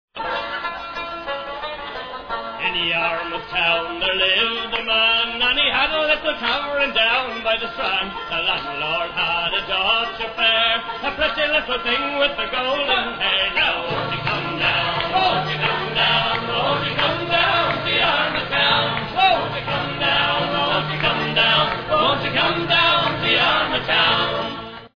guitar
bass